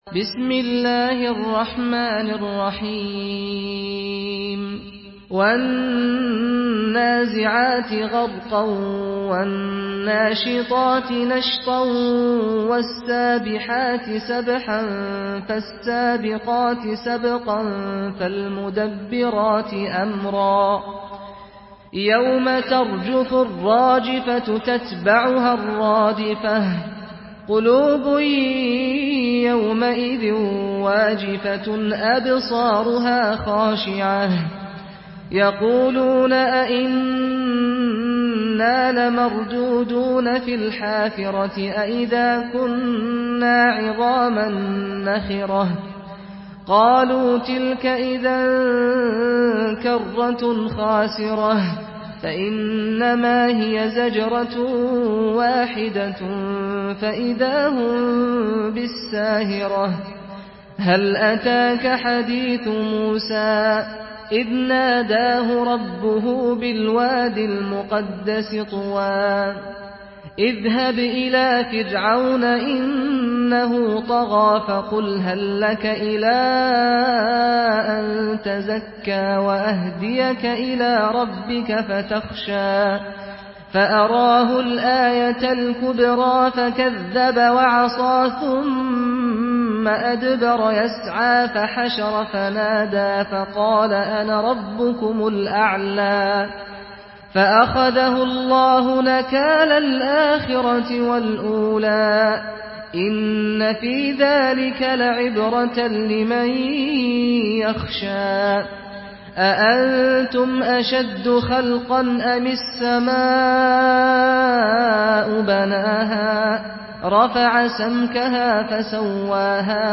Surah Naziat MP3 in the Voice of Saad Al-Ghamdi in Hafs Narration
Murattal